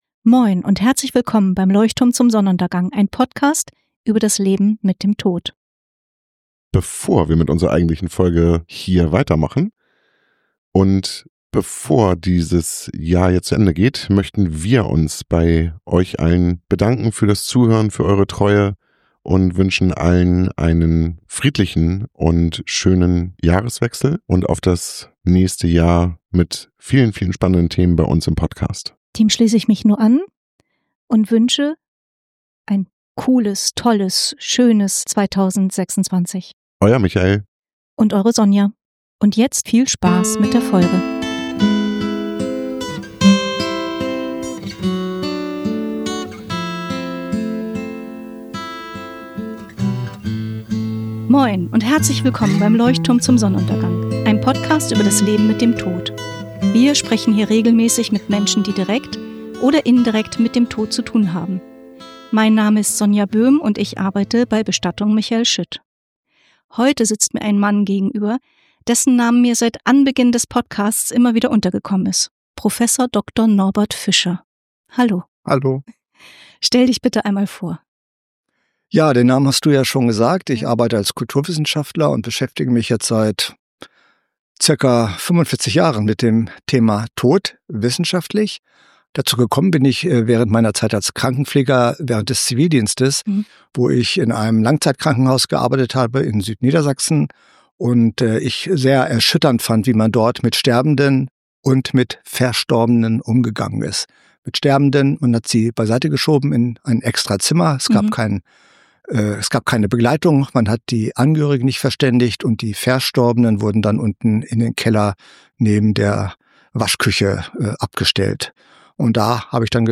LzS 21 - Im Gespräch